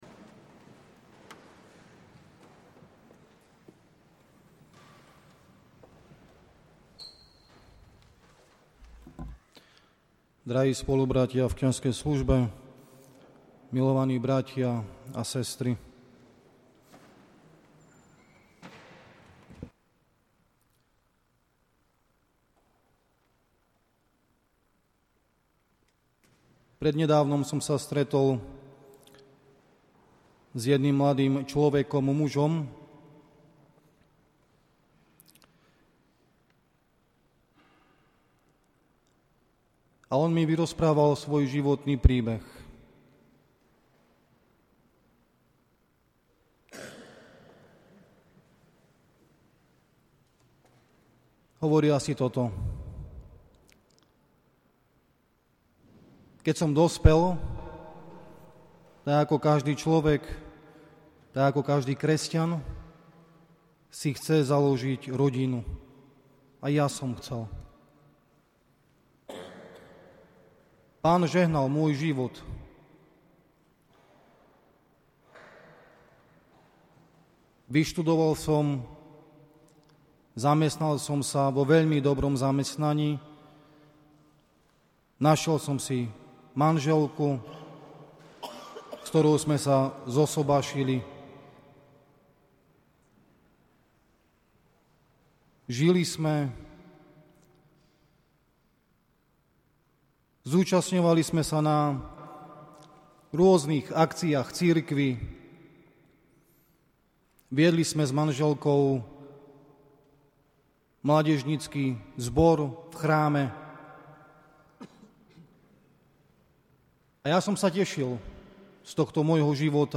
Tak ako každý mesiac aj dnes 25.4.2018 sme sa zišli k relikviám bl. Metoda.